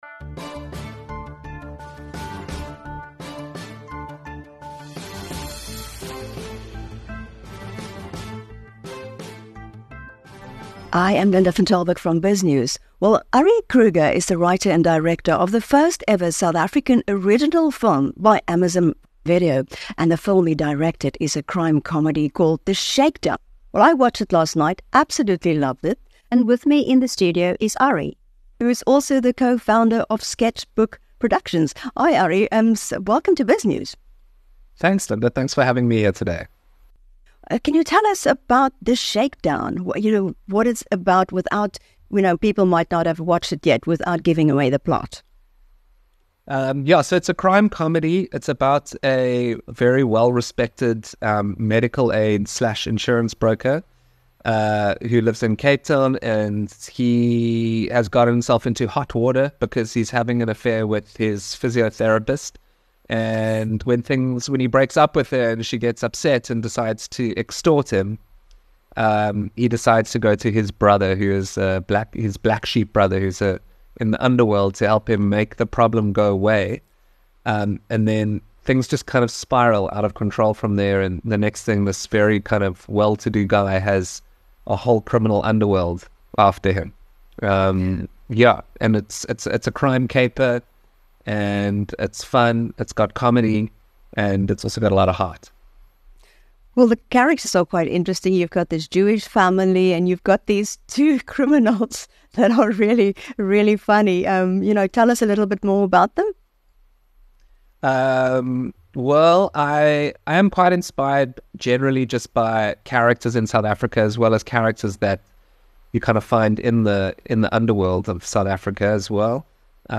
The Shakedown follows a broker entangled in an affair that leads to extortion, prompting him to seek assistance from his brother in the underworld. In an interview with BizNews